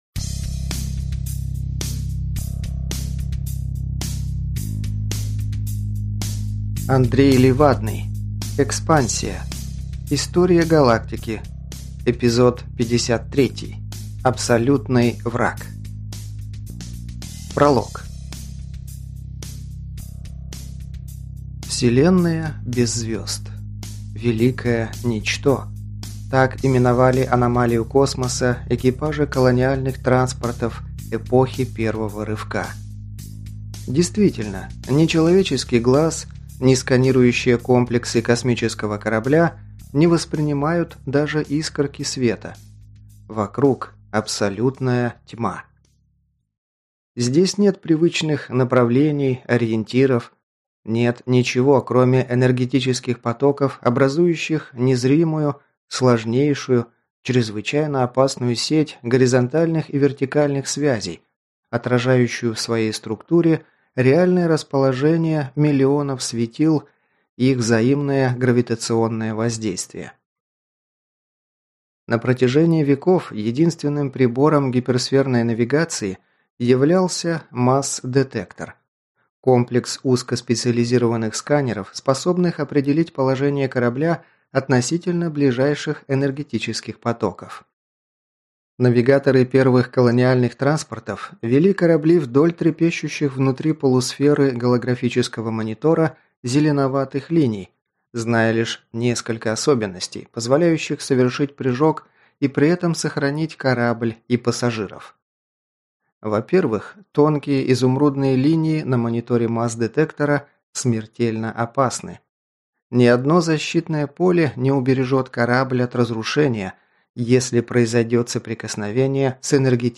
Аудиокнига Механоформы. Книга 2. Абсолютный враг | Библиотека аудиокниг